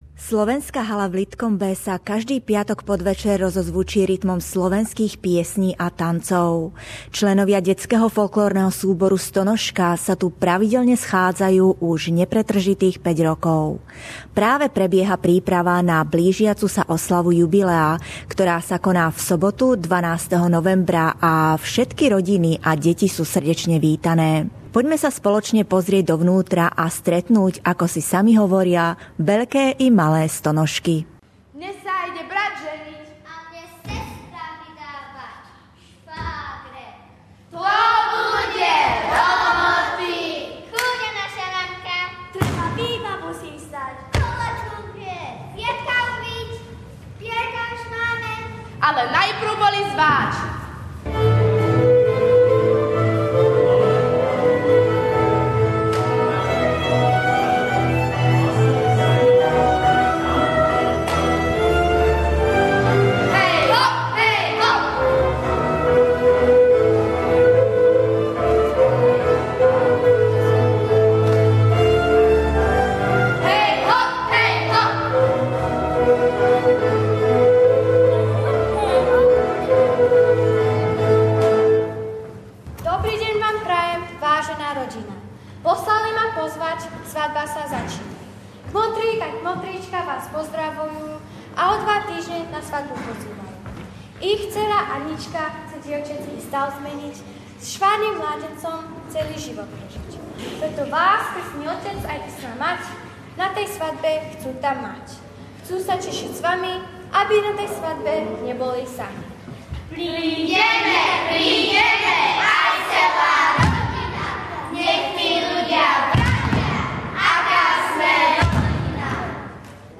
Reportáž